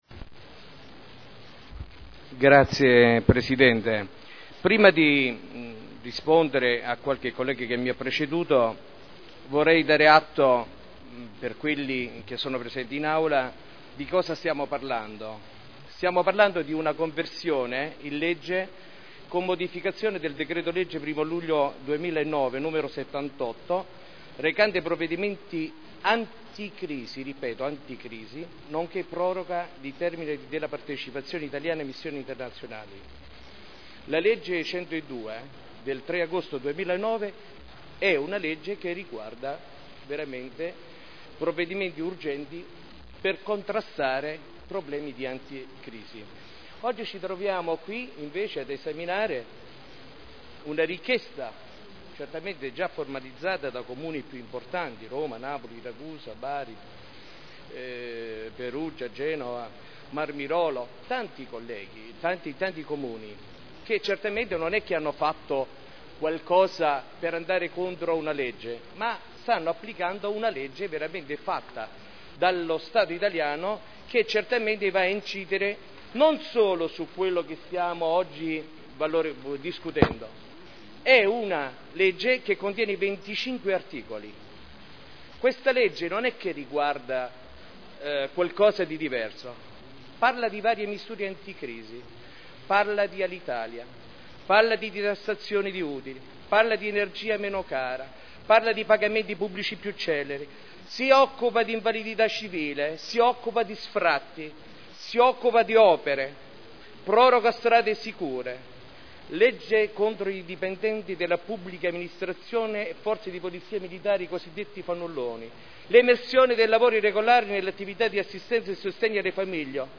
Maurizio Dori — Sito Audio Consiglio Comunale
Seduta del 20/12/2010. Dibattito su delibera: Definizione agevolata dei debiti derivanti da sanzioni relative a verbali di accertamento di violazioni al codice della strada elevati dal 1.1.2000 al 31.12.2004 (art. 15 comma 8 quinquiesdecies legge 3.8.2009 n. 102) (Commissione consiliare del 14 dicembre 2010)